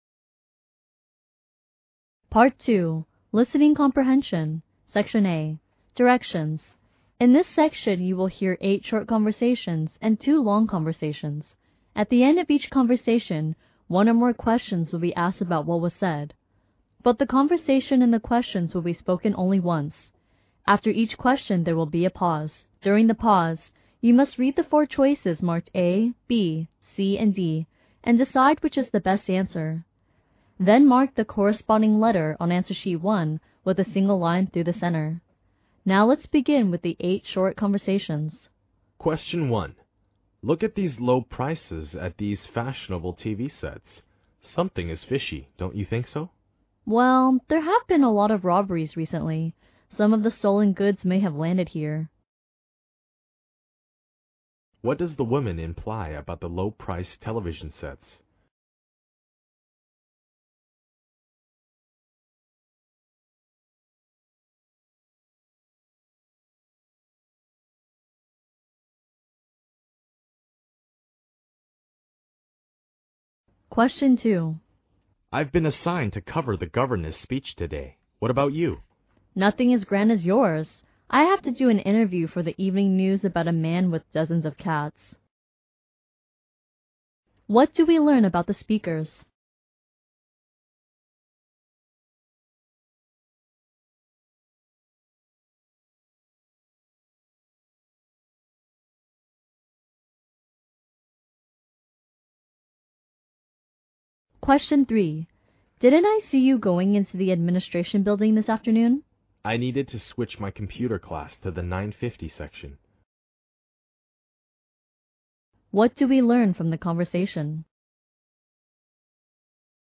Part II Listening Comprehension (30 minutes)